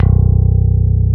Index of /90_sSampleCDs/Roland - Rhythm Section/BS _E.Bass 2/BS _Rock Bass
BS  ROCKBSB1.wav